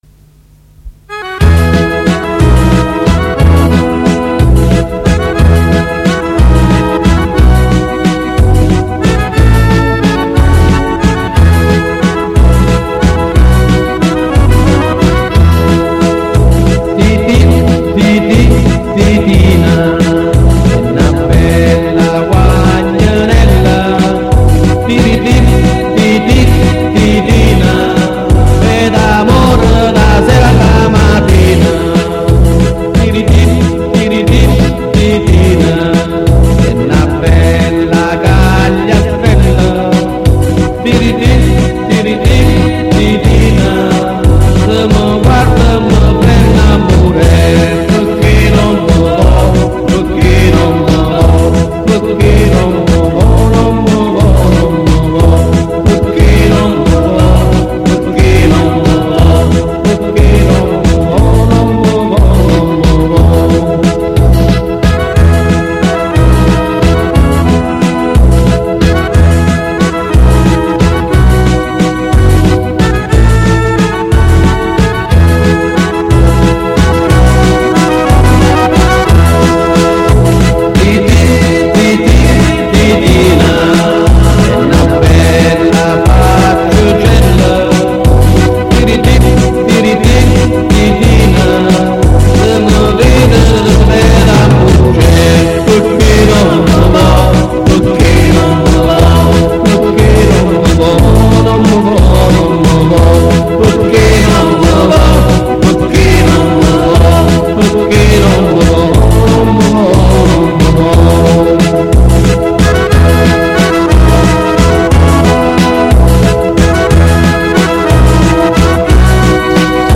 in dialetto cannese